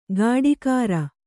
♪ gāḍikāra